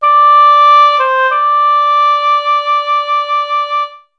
haubois.mp3